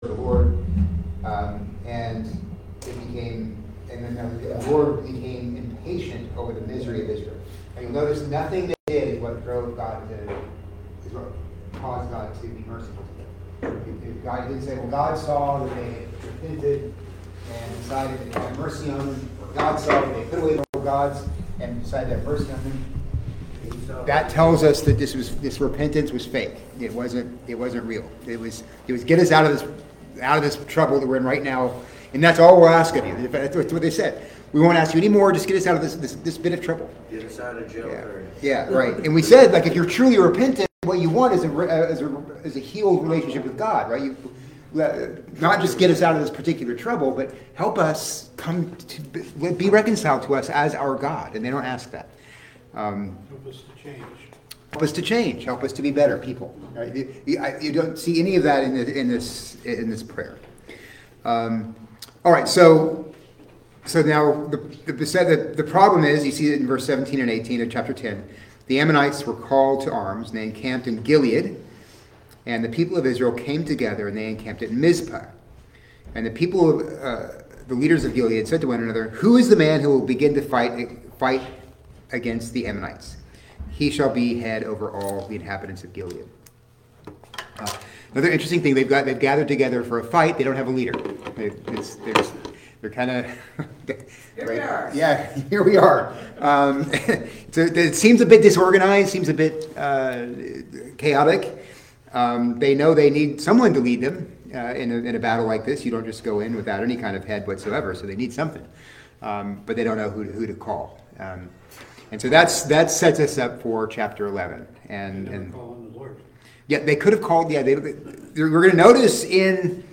Tuesday Morning Bible Study – March 4, 2025